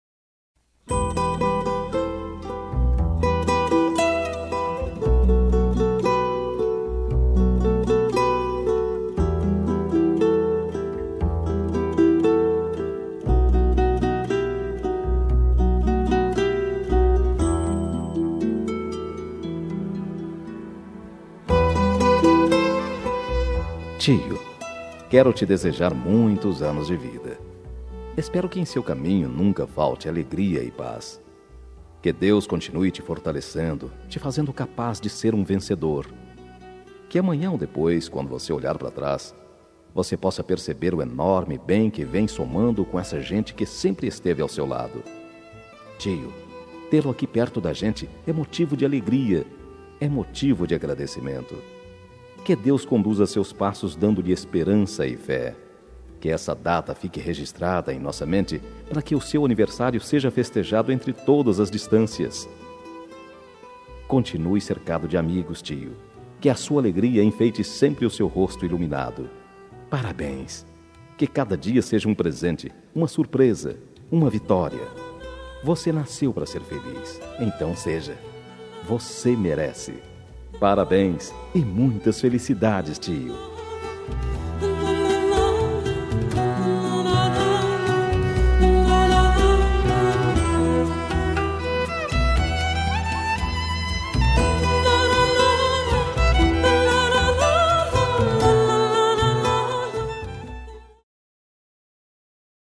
Aniversário de Tio – Voz Masculina – Cód: 910